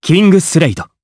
Siegfried-Vox_Kingsraid_jp.wav